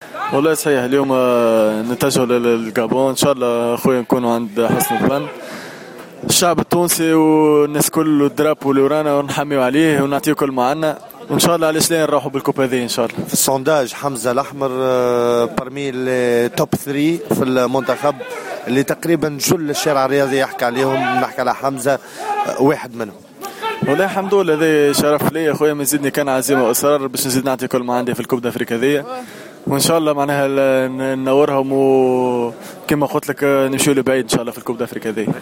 نبرة الثقة و التحدي تميز تصريحات لاعبي المنتخب